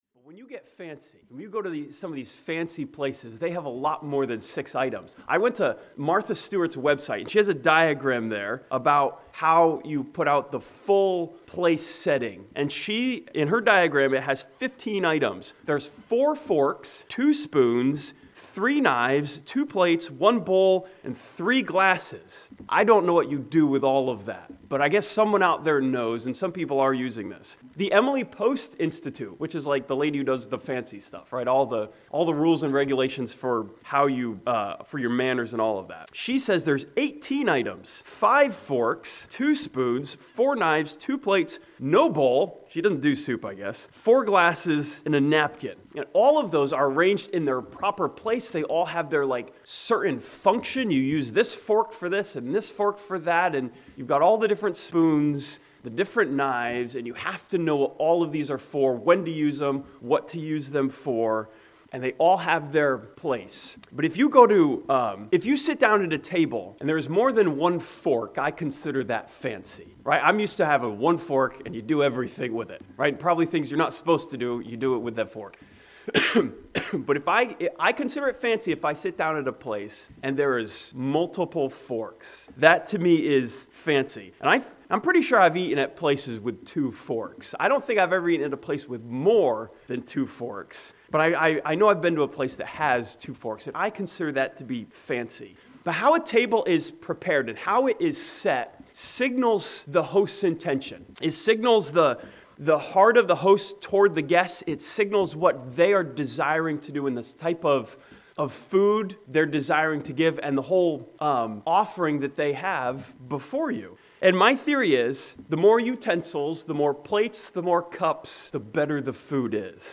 This sermon from Psalm 23 looks at the last two verses and study's the picture of a feast of God's goodness to us.